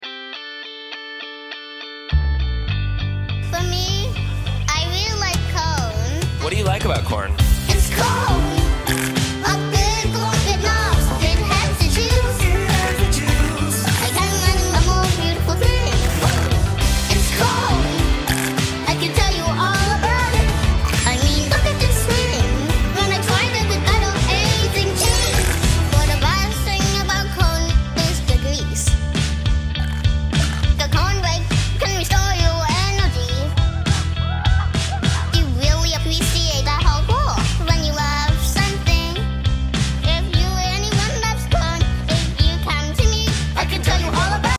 3D Printed Flexi Corn Cob sound effects free download